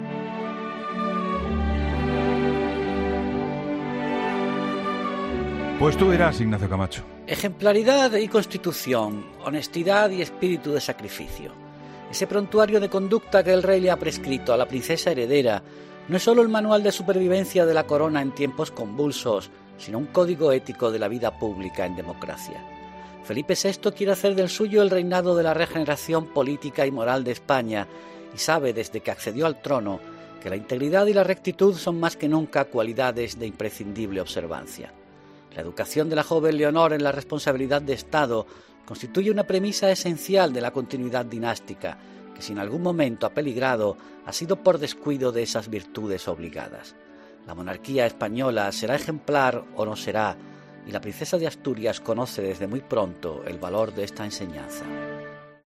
Ignacio Camacho comenta en 'La Linterna' el discurso del Rey Felipe VI durante la entrega del Toisón de Oro a la Princesa Leonor